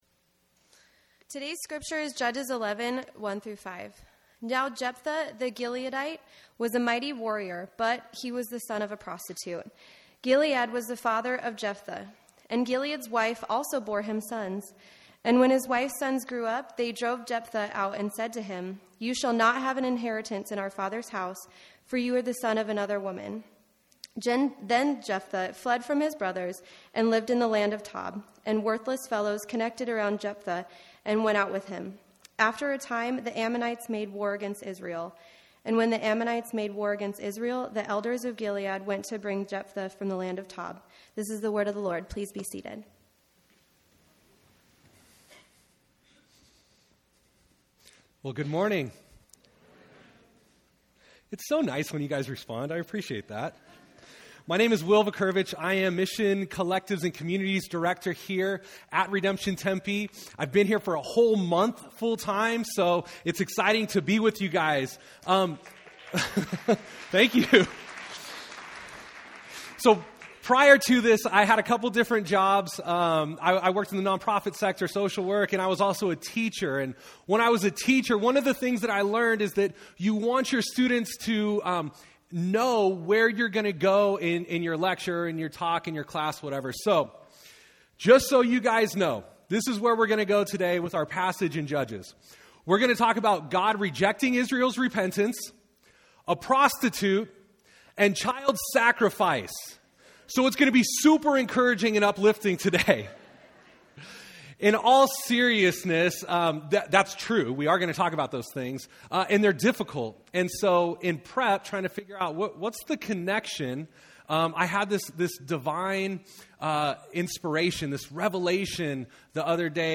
Sermons preached at the Tempe Congregation of Redemption Church.